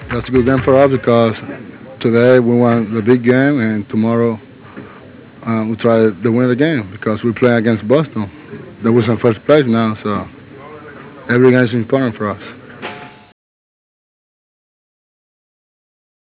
Here you'll find baseball-related sound files, from players talking about the game, to the Baseball Tonight theme song.